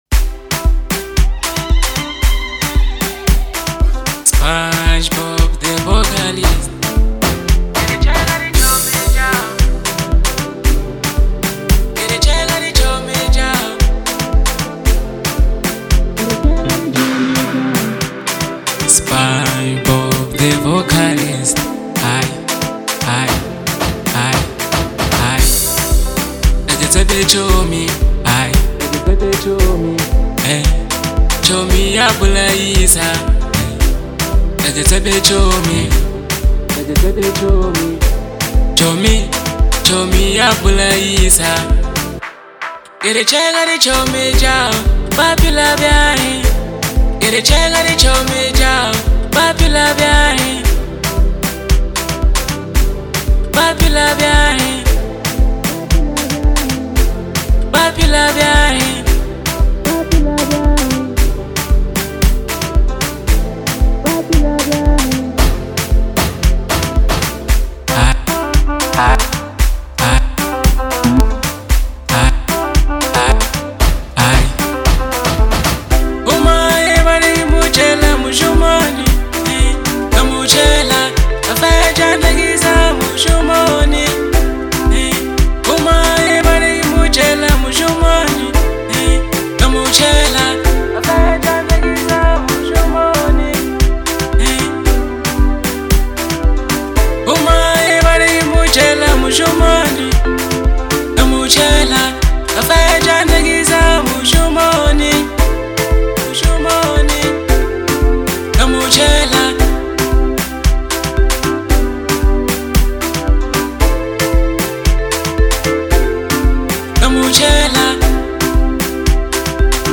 Genre : Bolo House